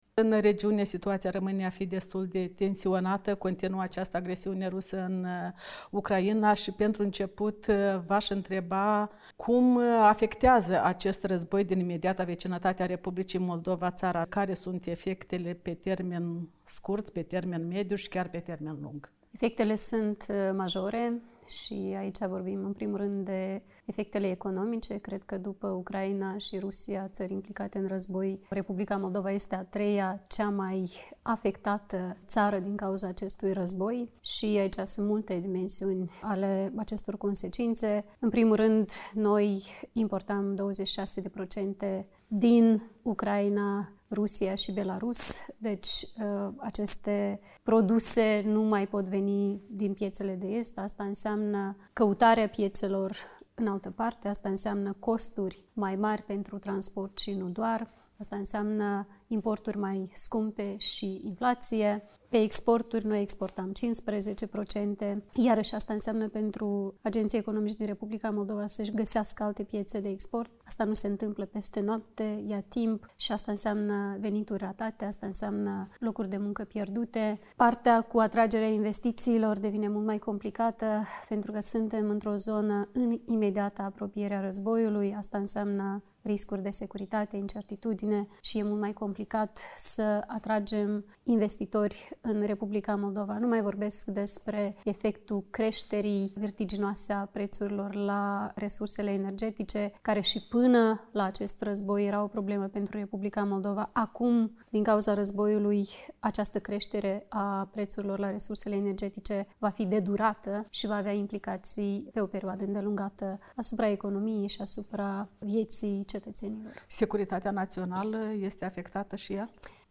Președinta Maia Sandu a apărat decizia Chișinăului de a nu se alinia sancțiunilor occidentale impuse Rusiei pentru invadarea Ucrainei, explicând că Republica Moldova are multiple „vulnerabilități”, atât în planul securității, cât și cel economic. Într-un interviu acordat Europei Libere, șefa...